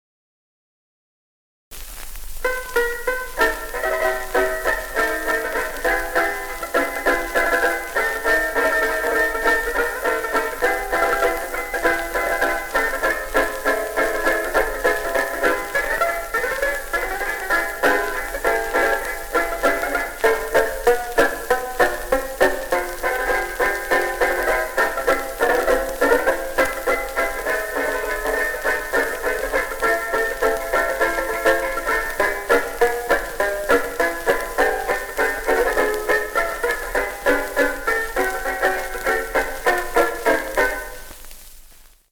tenor banjo